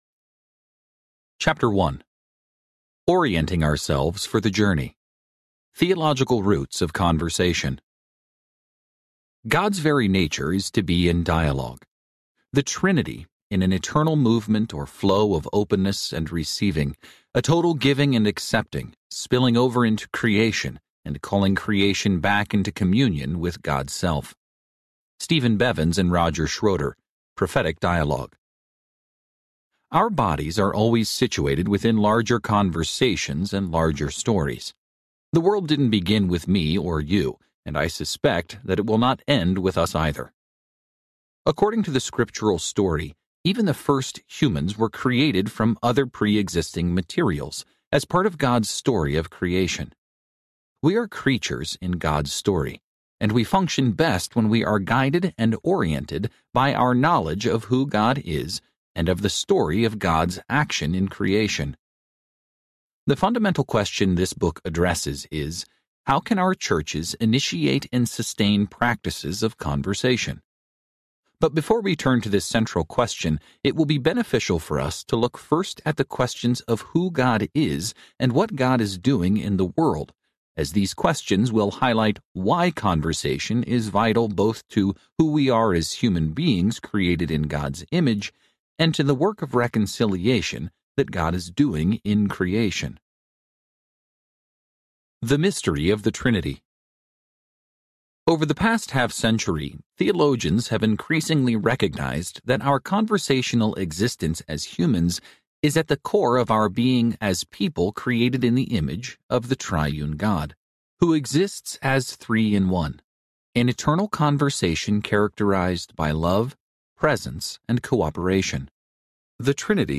How the Body of Christ Talks Audiobook
Narrator
6.1 Hrs. – Unabridged